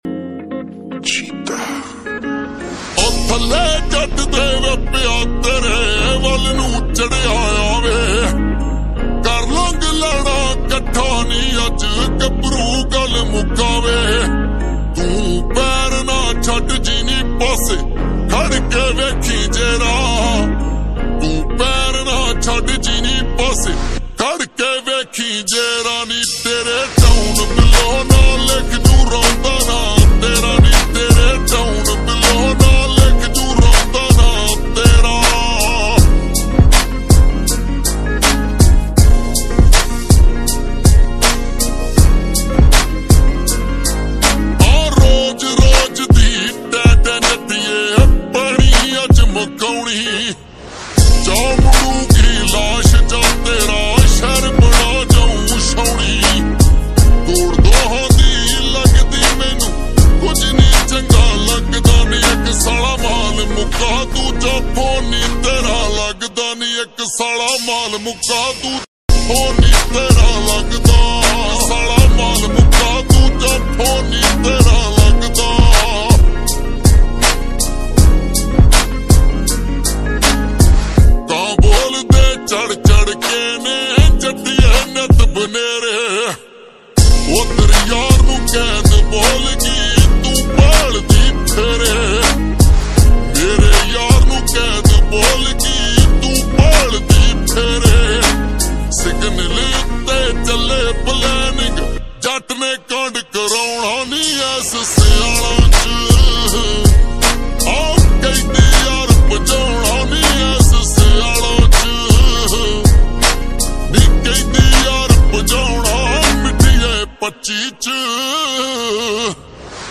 Full Slowed And Reverb